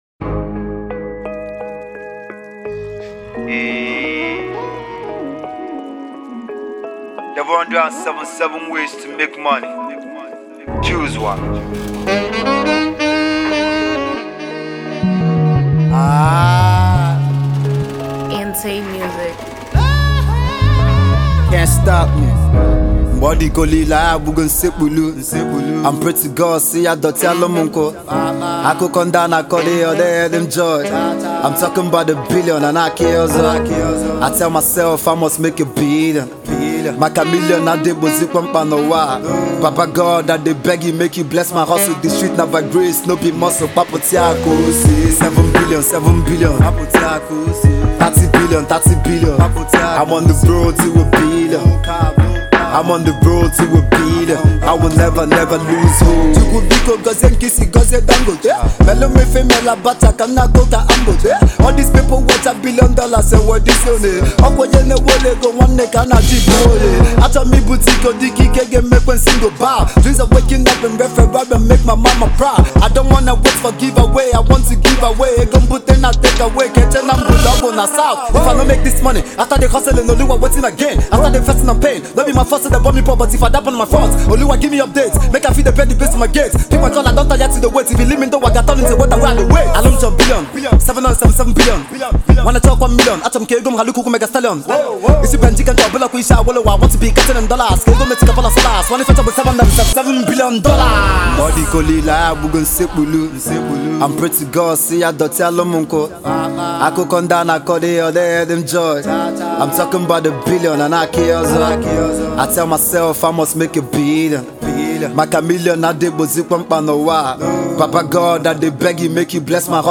T.R.A.P-infused tracks